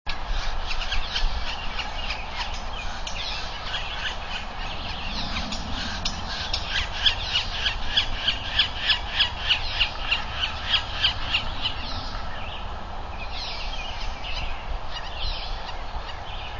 Yellow-bellied Sapsucker
sapsucker_weep_weep_calls_839.wav